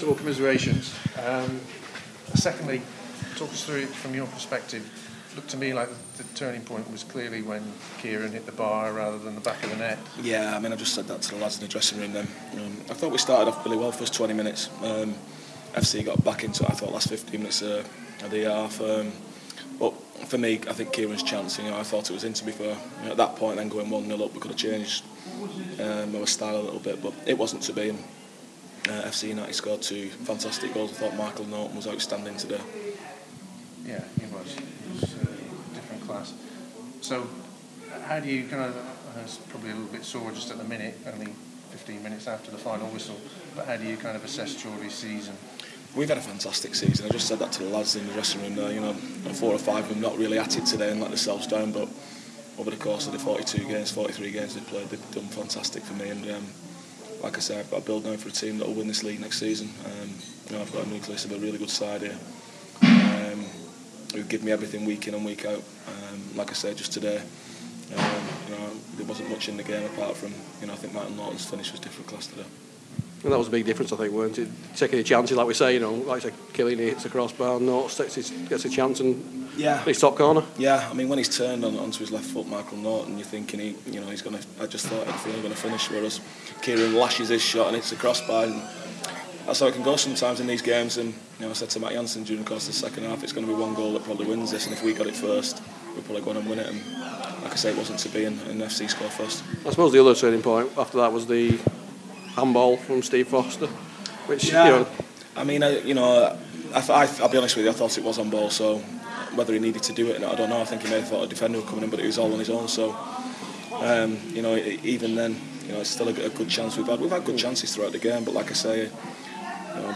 Post Match Interview - Garry Flitcroft - Chorley (a) - 28/04/12